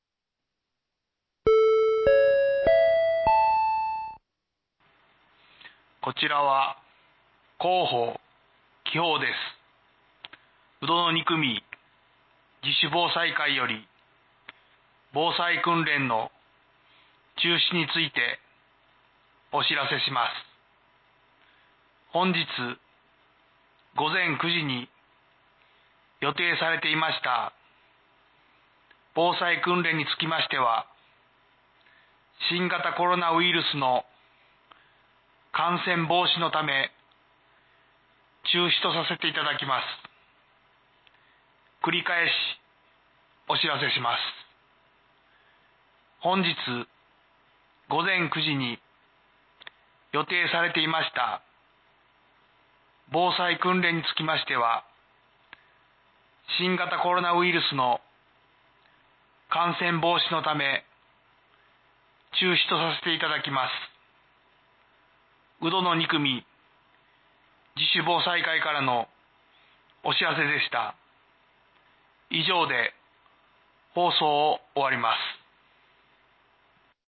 （鵜殿地区のみの放送です。）
放送音声